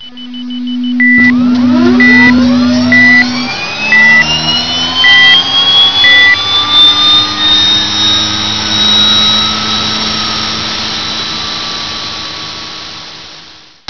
turbine_start_in.wav